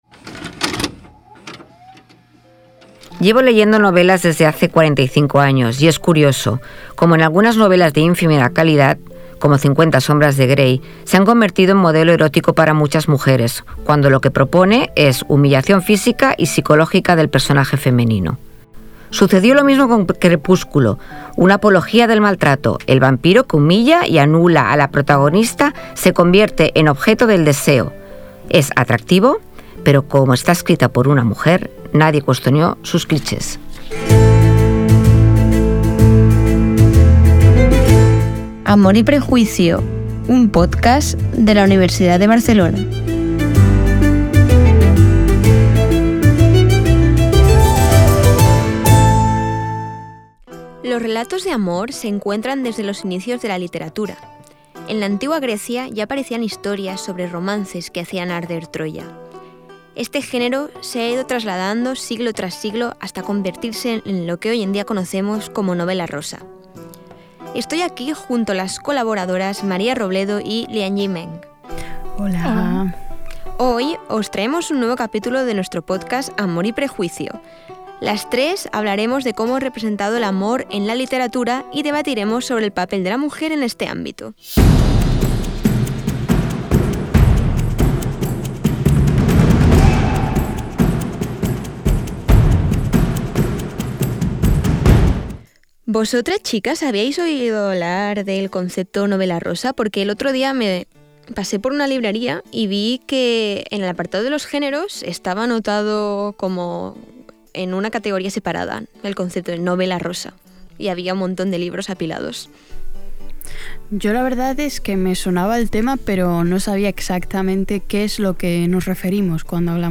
Podcast que trata el tema del amor y los diferentes ámbitos que lo configuran. Capítulo en el que las presentadoras hablan sobre el amor en la literatura y como se ha ido representando el papel de la mujer a lo largo de la historia.